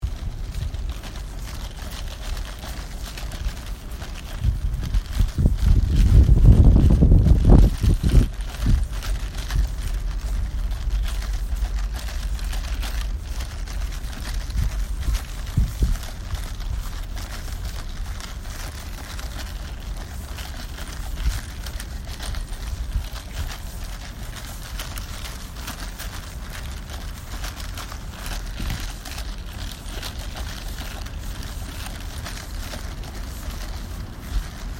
Field Recording 9
Pathway outside of Dunkin Donuts.
You will hear ice cubes shaking around in my coffee as I walk and the wind blowing into the microphone of my voice recorder. You may also hear faint footsteps walking by and a tiny jingle of someone’s keys towards the beginning of the clip.